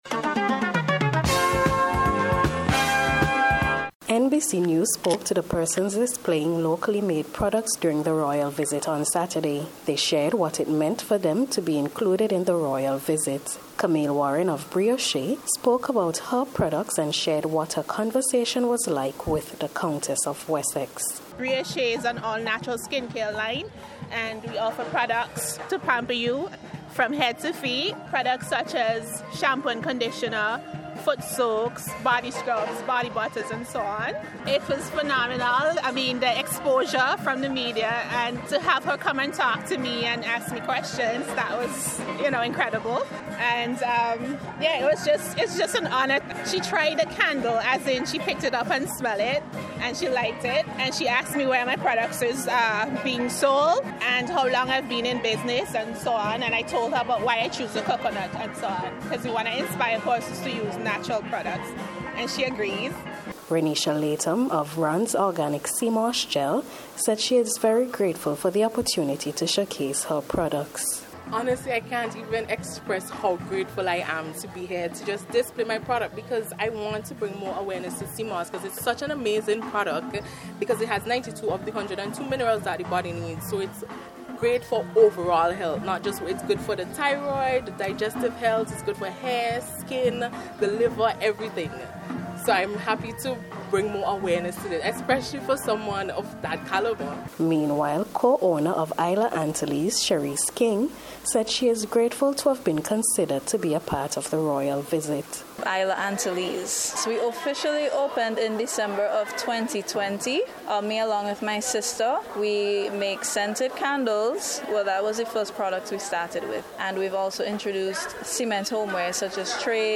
NBC’s Special Report for April 25th 2022